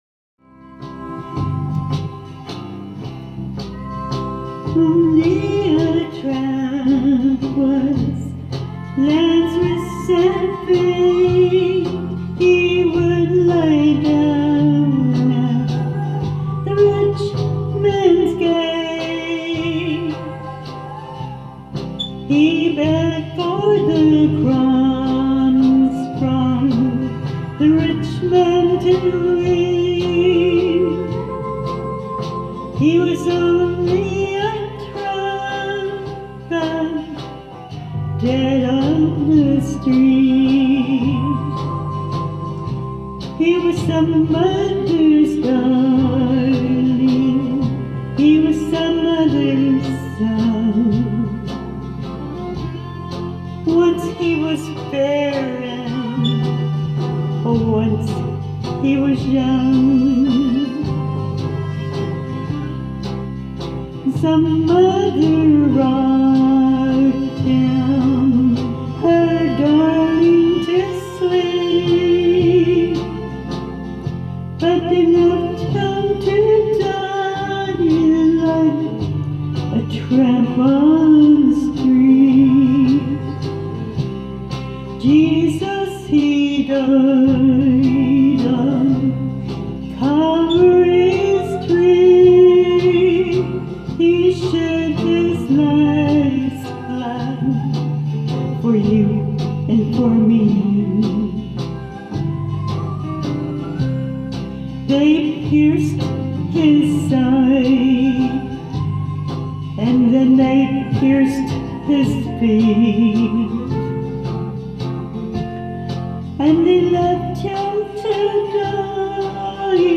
2019 Sunday Sermon Ref